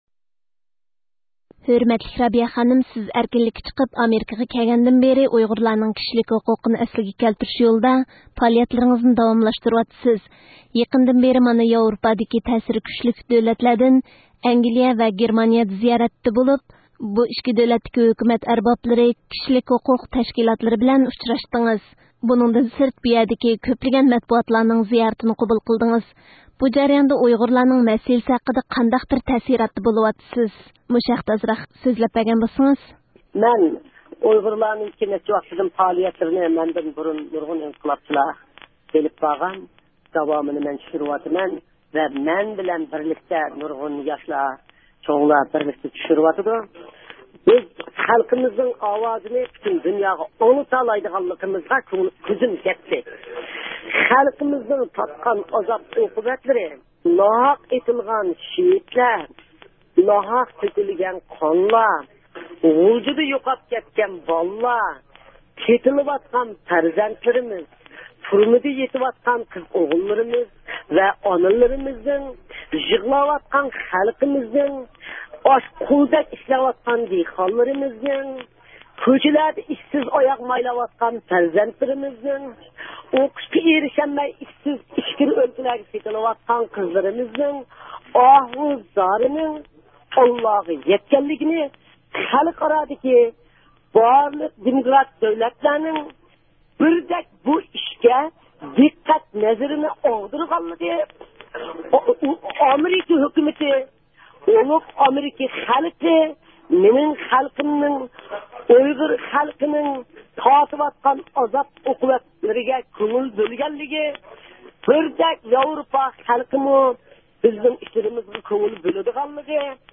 بۇ پروگراممىمىزدا سىلەر، رابىيە خانىمنىڭ ئىككى قېتىملىق ياۋرۇپا زىيارىتى داۋامىدا ھېس قىلغانلىرى ھەققىدە ئۆتكۈزۈلگەن سۆھبەتنى ئاڭلايسىلەر .